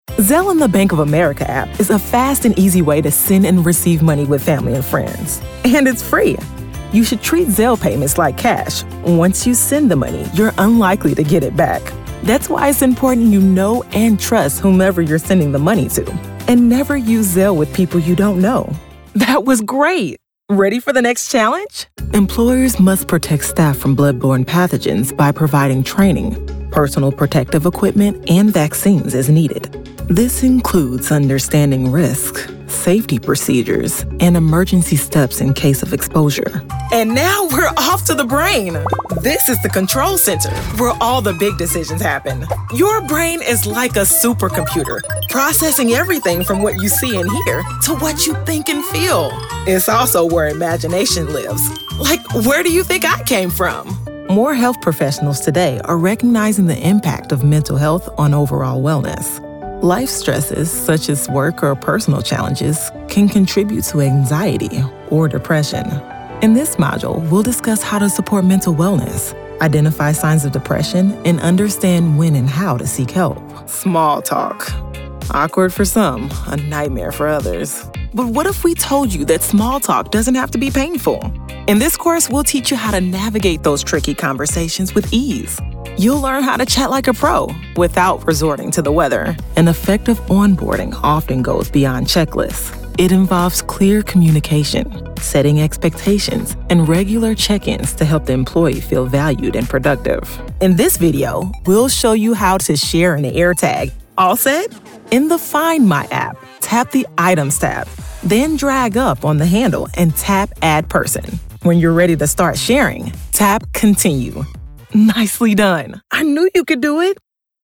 Female
Approachable, Authoritative, Bright, Confident, Conversational, Cool, Corporate, Energetic, Engaging, Friendly, Natural, Reassuring, Sarcastic, Upbeat, Versatile, Warm, Young
Southern, Midwest (native)
My voice has been described as warm, sultry, wise, grounded, unpretentious, authoritative, and emotionally engaging, perfect for projects that require a balance of professionalism and relatability.
Dry read.mp3
Audio equipment: Audio Sigma interface and Focusrite Scarlett Solo backup in a sound-treated, carpeted home studio with acoustic treatment for clean, broadcast-quality audio.